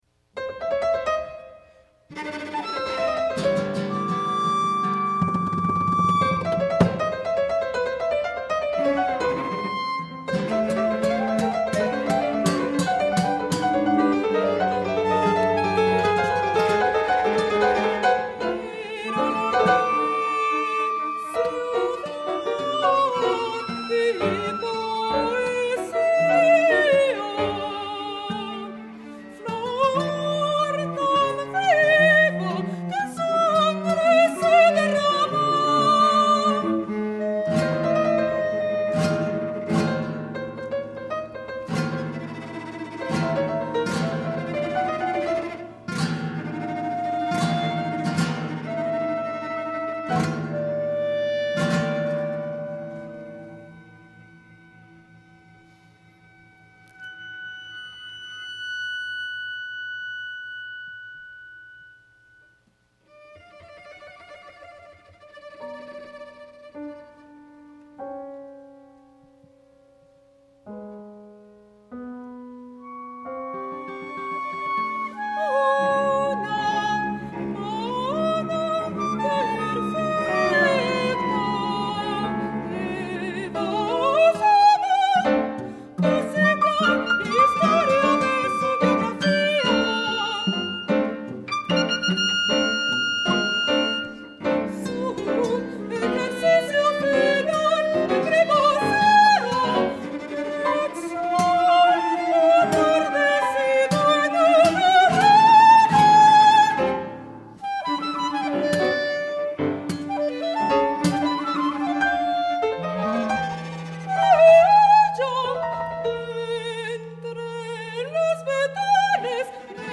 Para soprano, clarinete, guitarra, piano y violonchelo. (Versión en vivo).